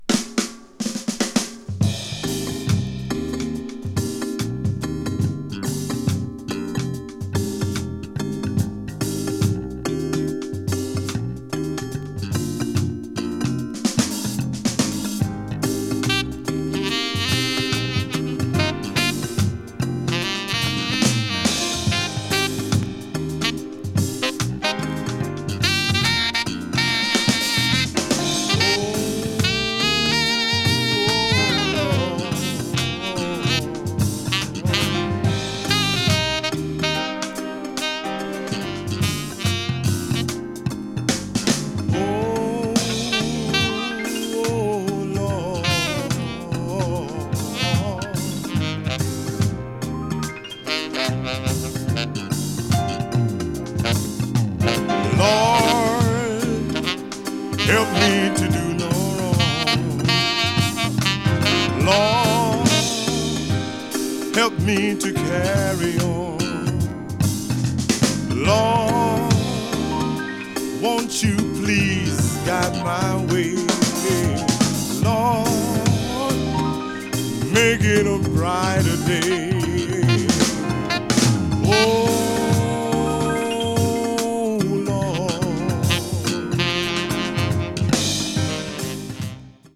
media : EX/EX(some slightly noises.)
superb reggae tune
blues jazz   post bop   reggae   spritual jazz